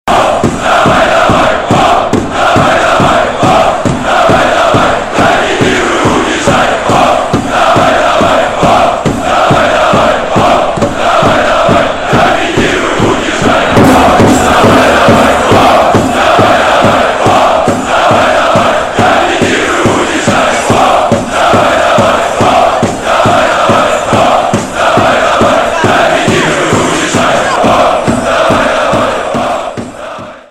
• Качество: 192, Stereo
мотивирующие
Спортивные
фанатские
болельщики